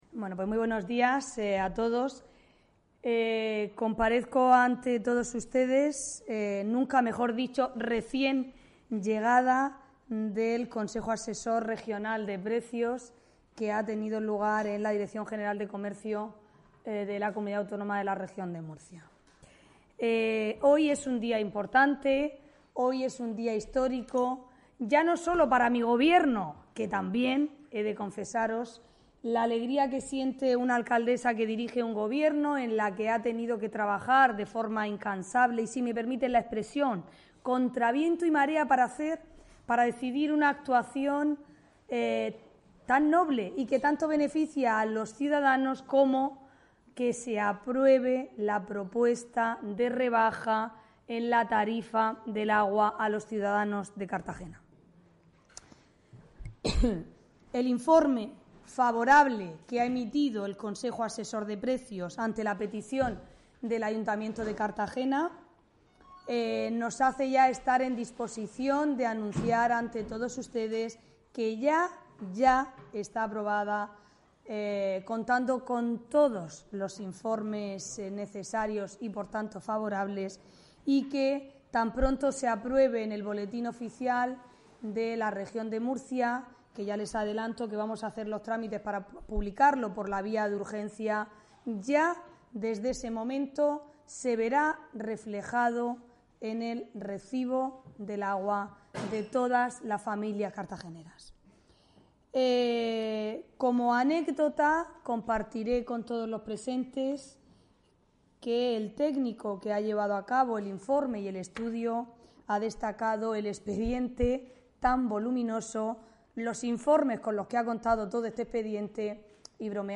Audio: Declaraciones de la alcaldesa Ana Bel�n Castej�n sobre bajada del recibo del agua (MP3 - 3,76 MB)